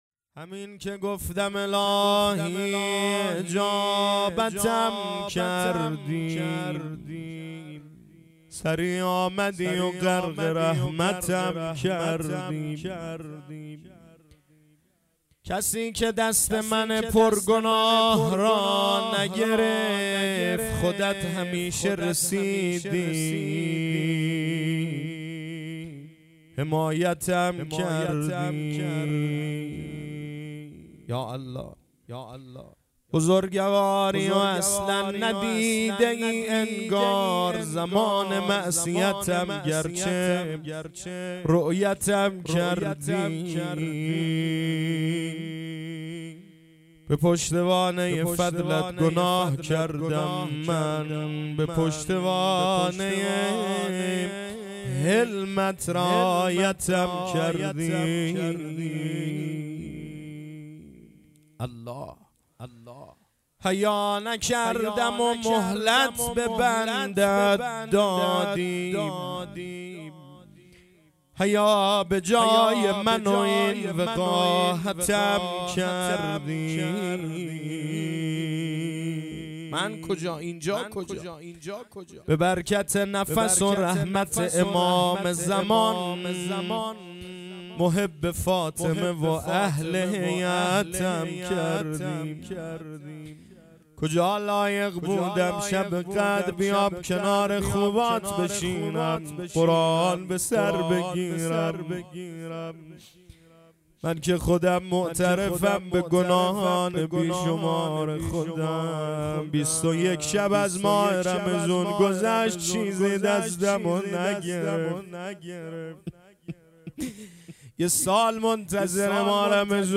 مناجات
احیاء شب قدر (شب بیست و یکم رمضان) عزاداری شهادت امیرالمؤمنین علیه السلام